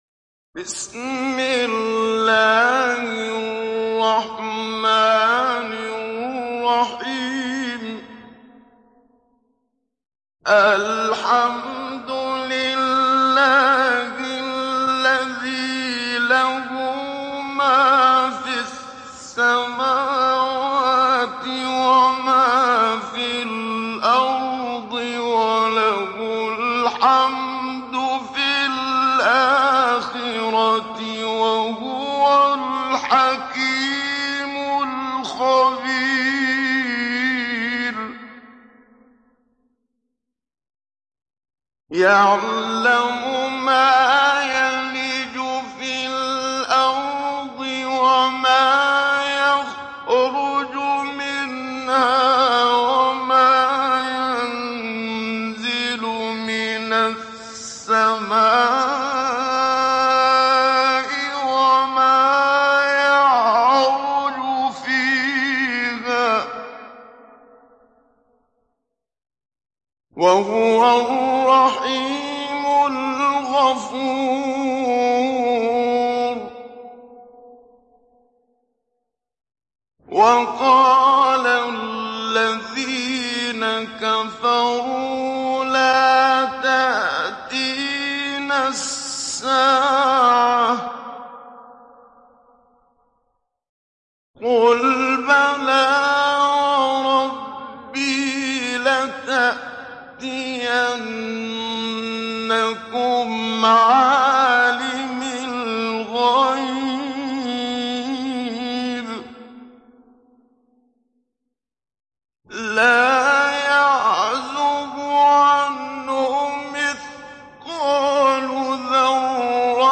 Sebe Suresi İndir mp3 Muhammad Siddiq Minshawi Mujawwad Riwayat Hafs an Asim, Kurani indirin ve mp3 tam doğrudan bağlantılar dinle
İndir Sebe Suresi Muhammad Siddiq Minshawi Mujawwad